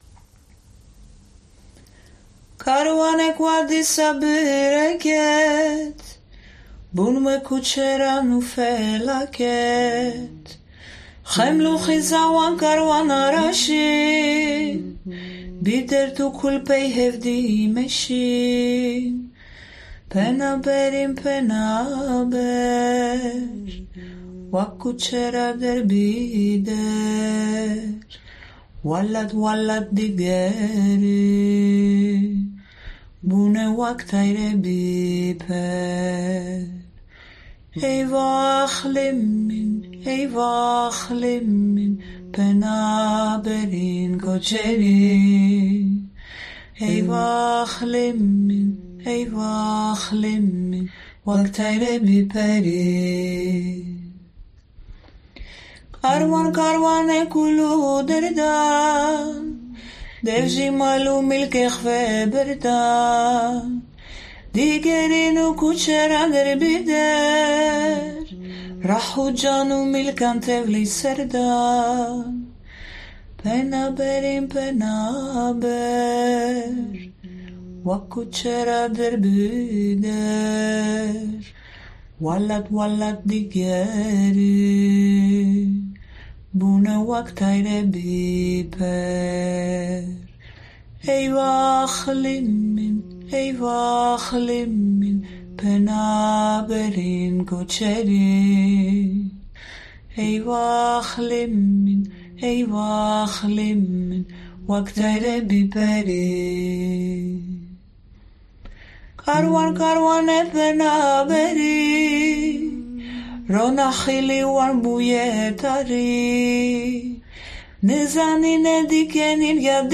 voice